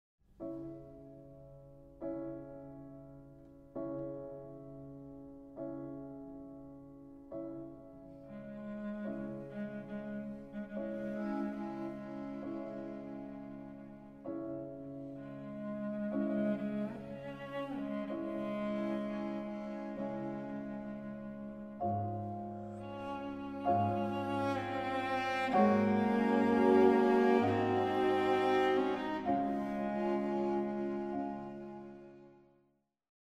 Piano Trio in E minor